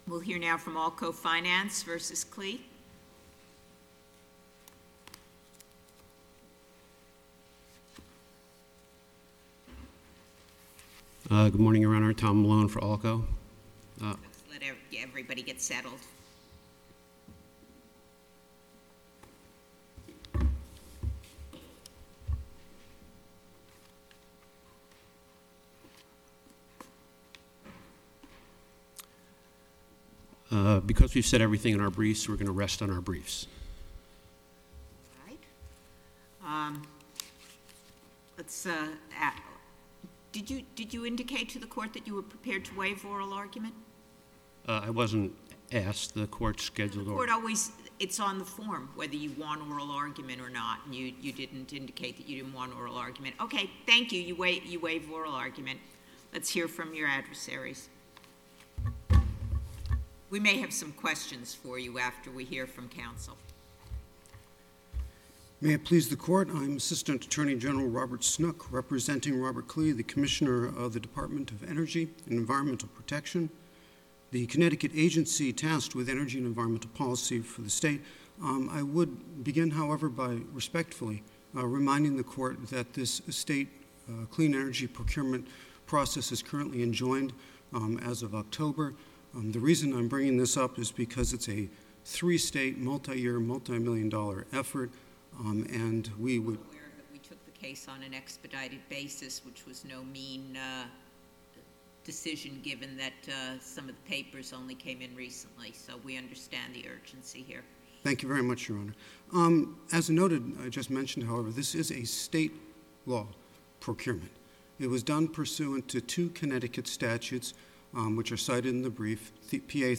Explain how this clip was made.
allco-2nd-cir-oral-argument.mp3